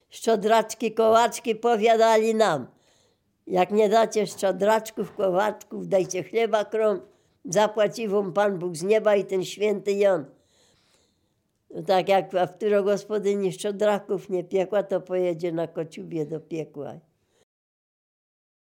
Dolny Śląsk, powiat bolesławiecki, gmina Nowogrodziec, wieś Zebrzydowa
Oracja
gody kolędowanie kolędy zima podłazy szczedraki szczodraki oracje